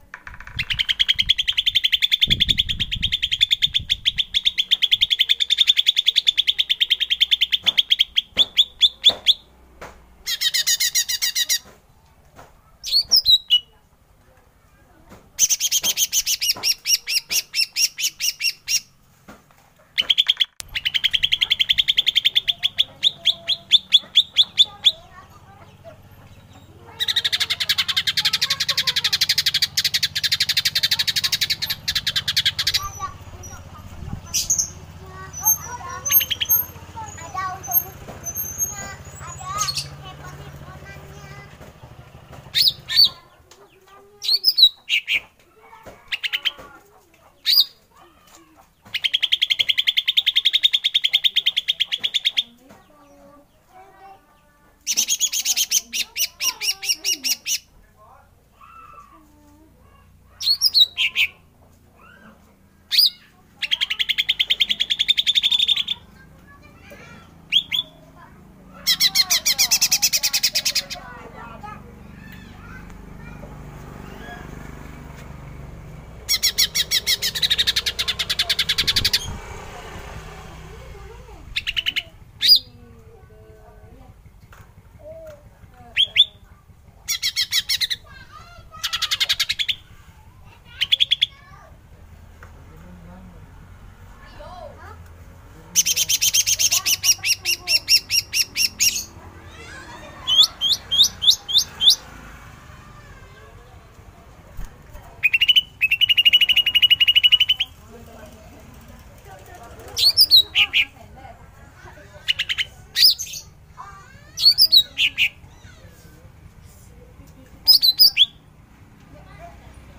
Suara Cucak Ijo Mini Betina
Kategori: Suara burung
Keterangan: Unduh suara panggilan Cucak Ijo Mini betina yang handal dalam format MP3.
suara-cucak-ijo-mini-betina-id-www_tiengdong_com.mp3